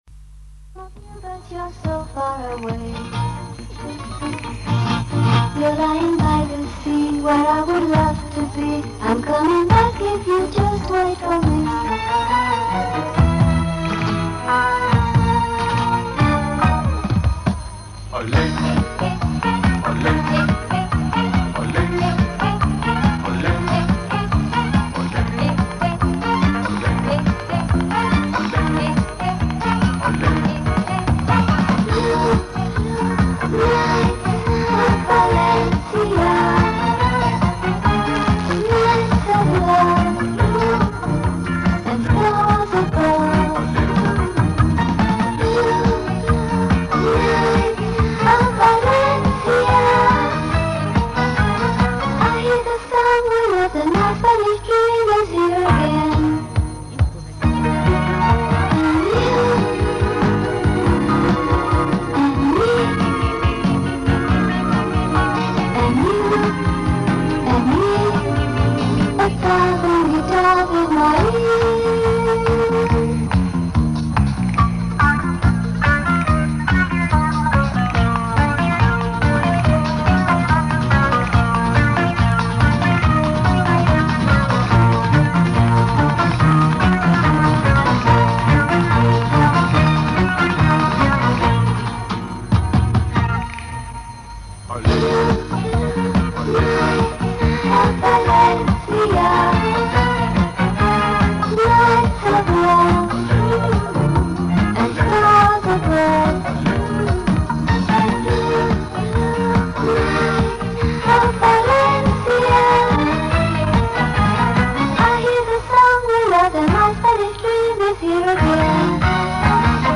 Первая, определнно болгарская исполнительница.